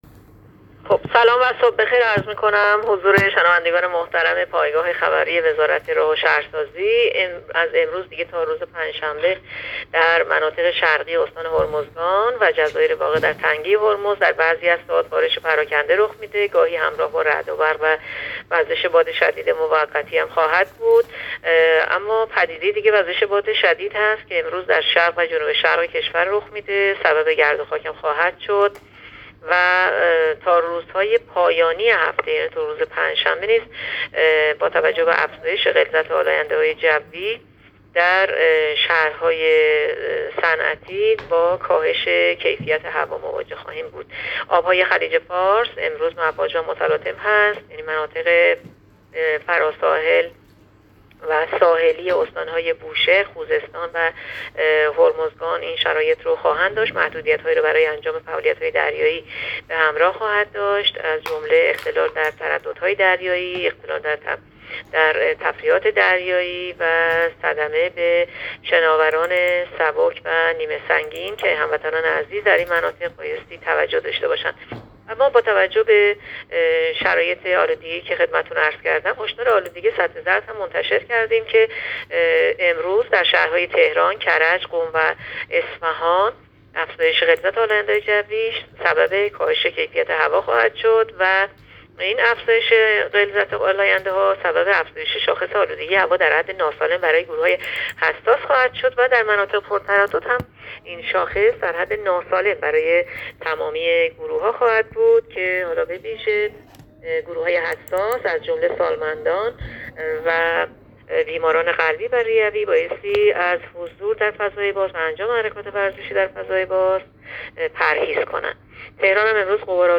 گزارش رادیو اینترنتی پایگاه‌ خبری از آخرین وضعیت آب‌وهوای ۲ دی؛